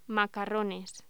Locución: Macarrones
voz